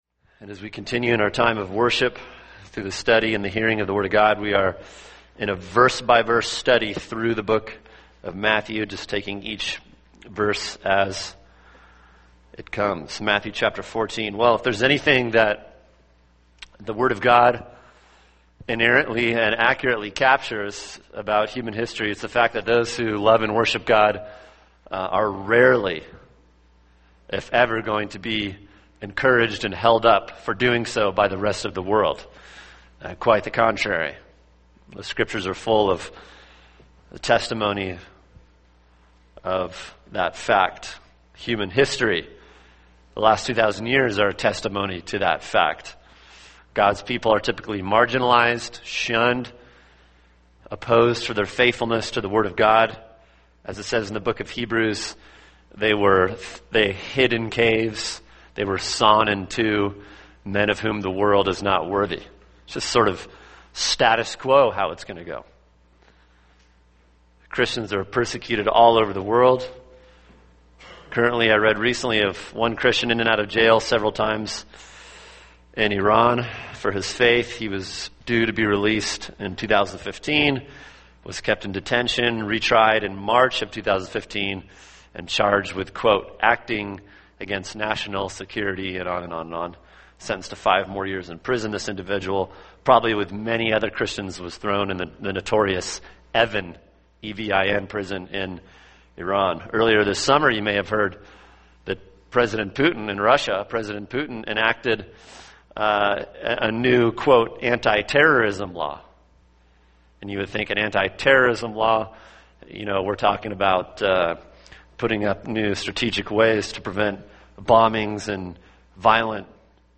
[sermon] Matthew 14:1-13a – Lessons from an Ancient Soap Opera | Cornerstone Church - Jackson Hole